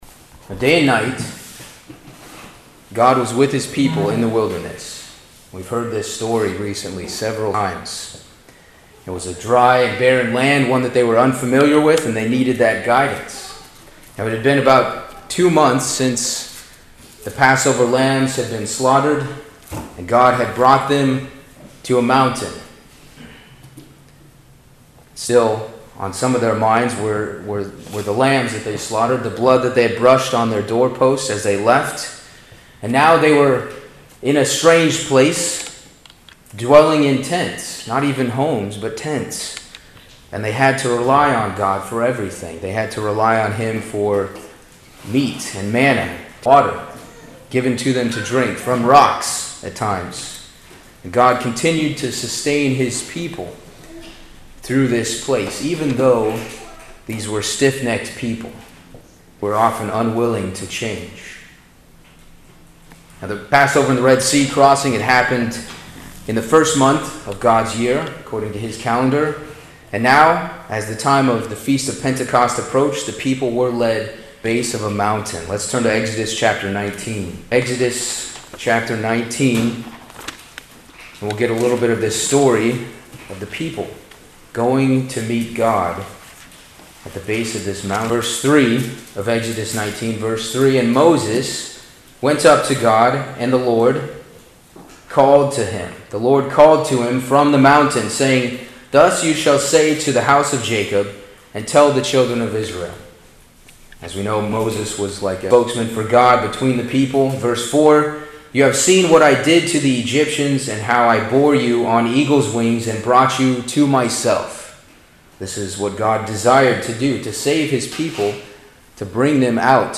Sermons
Given in Hartford, CT